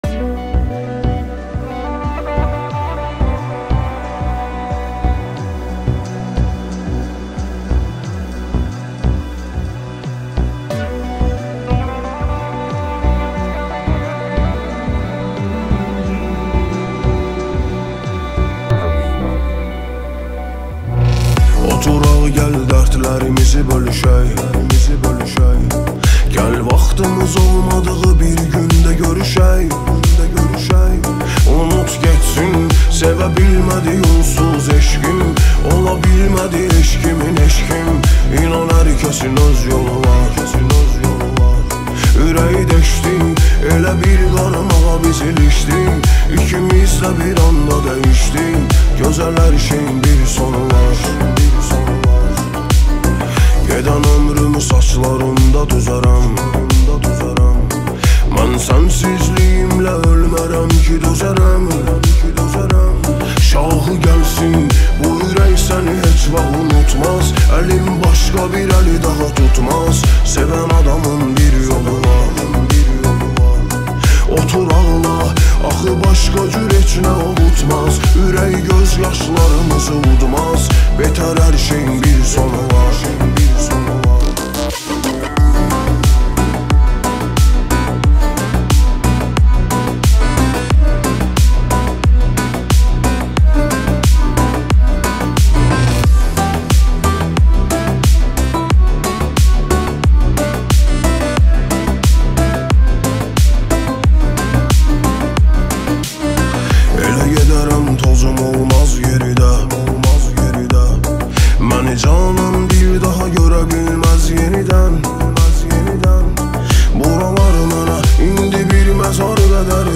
наполнена чувственностью и меланхолией